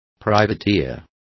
Complete with pronunciation of the translation of privateers.